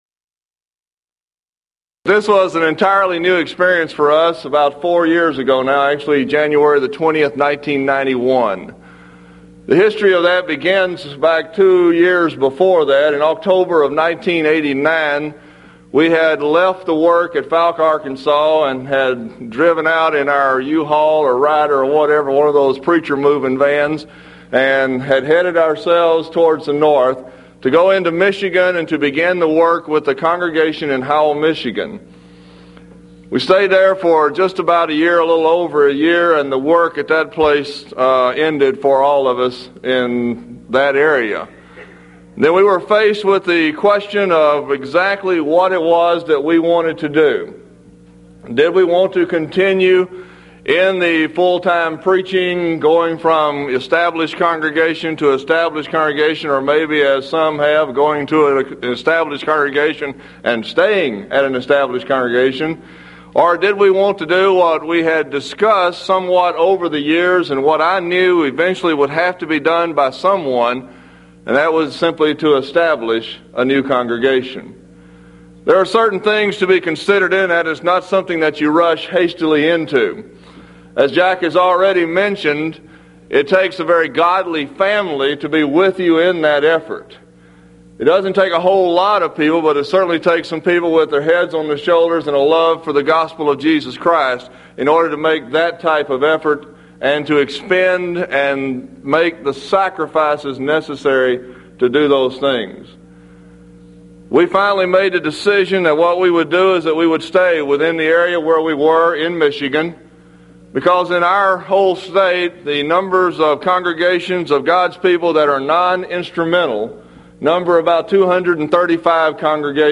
Event: 1994 Mid-West Lectures
Filed Under (Topics): Preaching